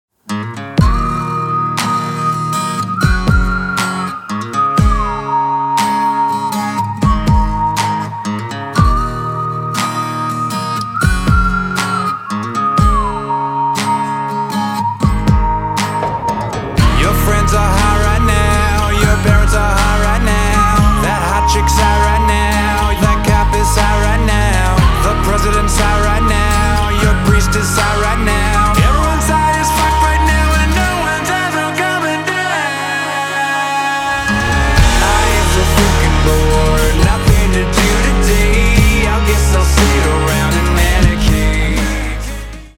• Качество: 320, Stereo
свист
мужской вокал
Alternative Rock
whistle